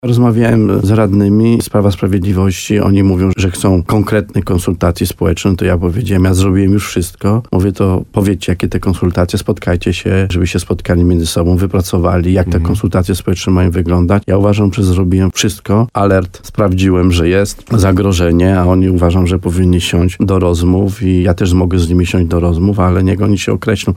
Stanisław Kuzak powiedział w programie Słowo za Słowo na antenie radia RDN Nowy Sącz, że trwają rozmowy z radnymi na ten temat.